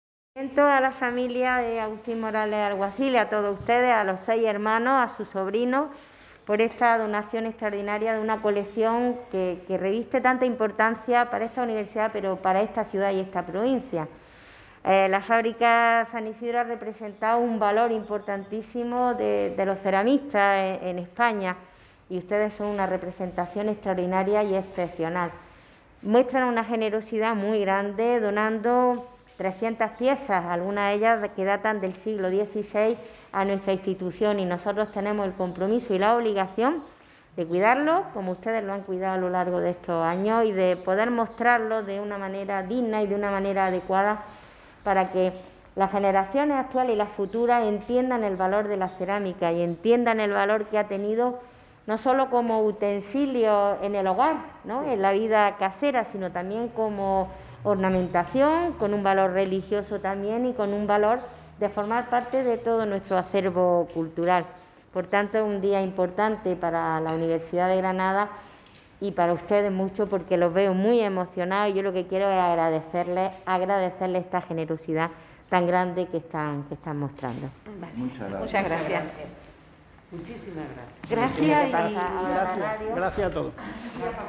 Audios de la firma del convenio:
Intervención de Pilar Aranda